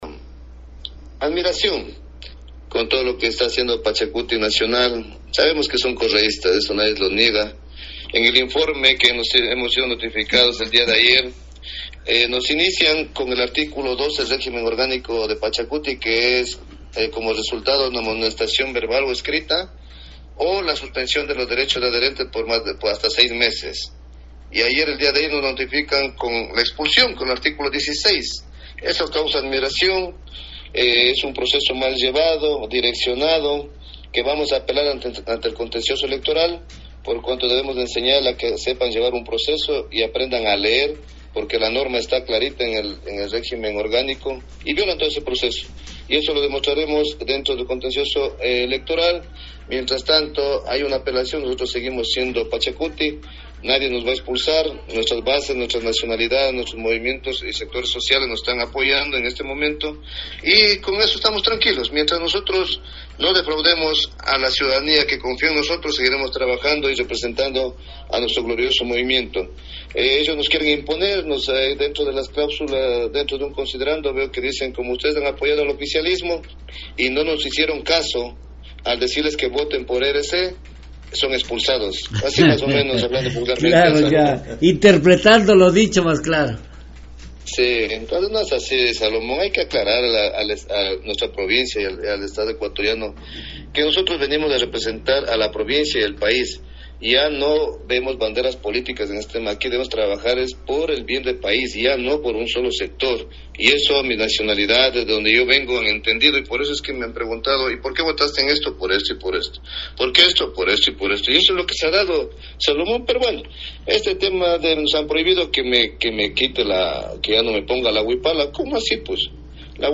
En una entrevista en Nina radio de Puyo el 17 de septiembre, el legislador respondió a Guillermo Churuchumbi coordinador nacional de PK, a quien considera ser el impulsador de la expulsión debido a que los seis legisladores se han resistido a cumplir sus órdenes; es decir no se alinearon para votar en el parlamente junto a los asambleístas de la Revolución Ciudadana (RC).